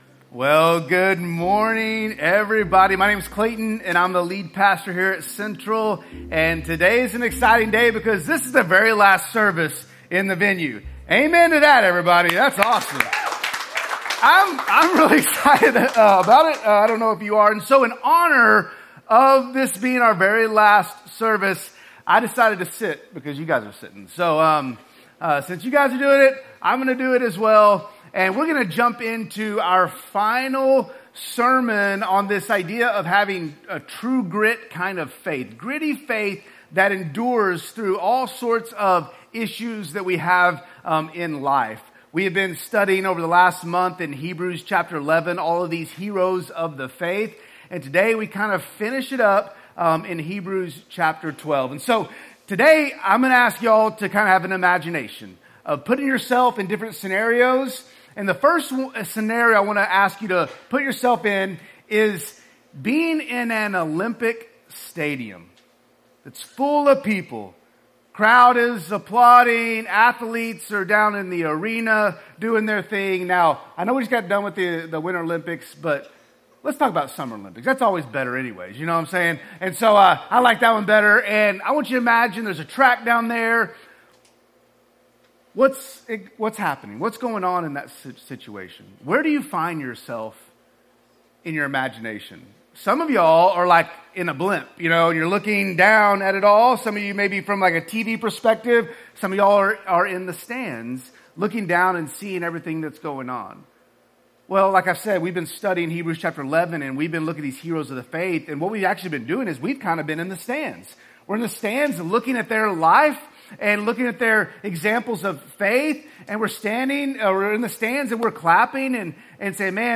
Sermons | Central Baptist Church Owasso